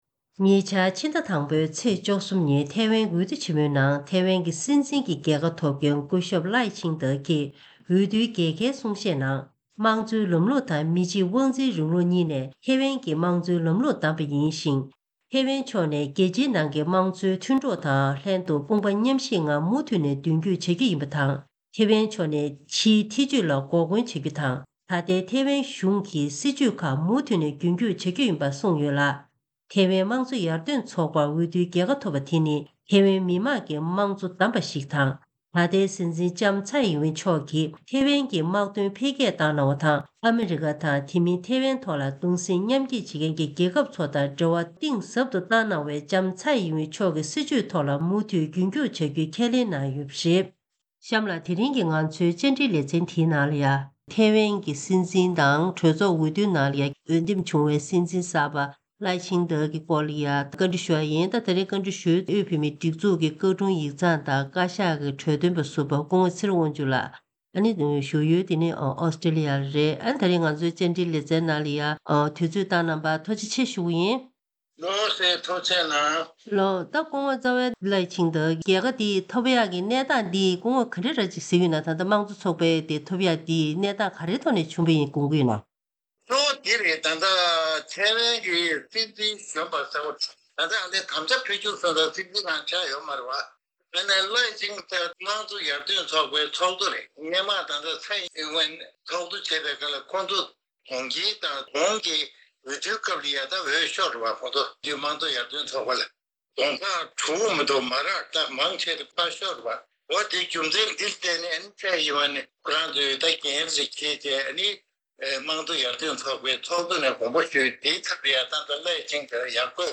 ཉེ་ཆར་འོས་འདེམས་བྱུང་བའི་ཐེ་ཝན་གྱི་སྲིད་འཛིན་Lai Ching-te ཡི་སྐོར་དམིགས་བསལ་བཅར་འདྲི་ཞུས་པའི་ལེ་ཚན།